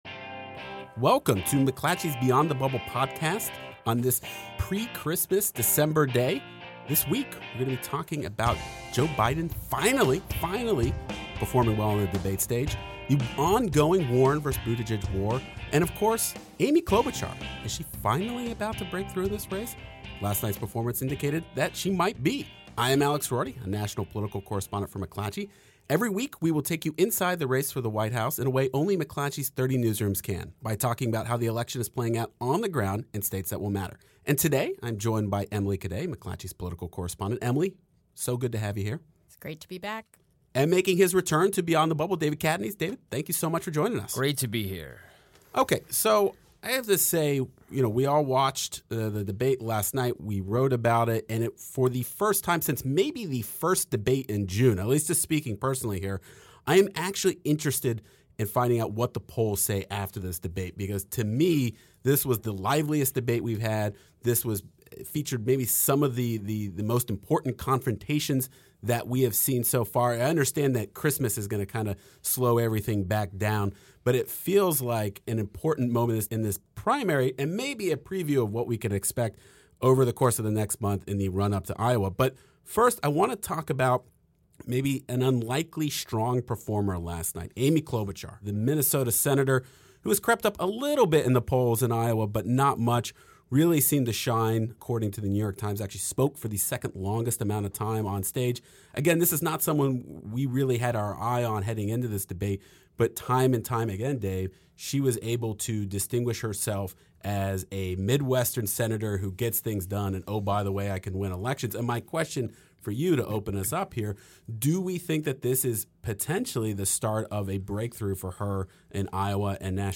Political correspondents